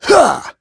Clause_ice-Vox_Attack2.wav